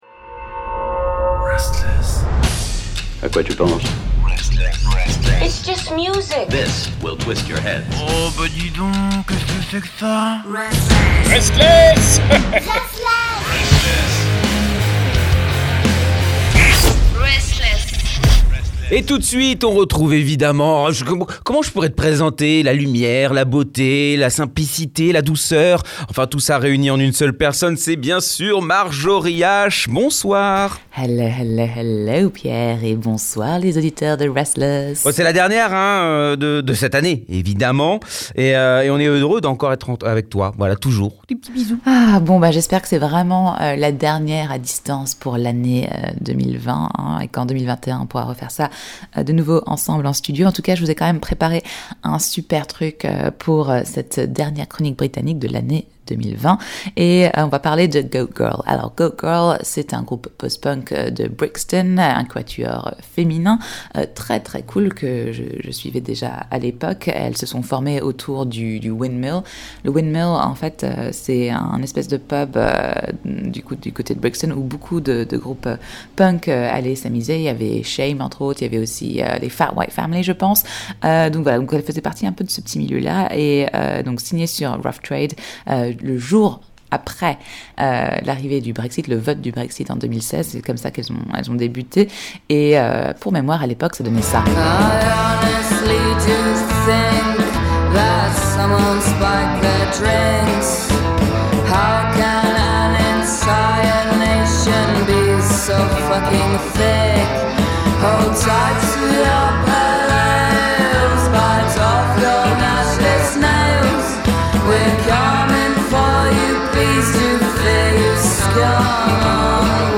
La nouveauté rock Britannique présentée par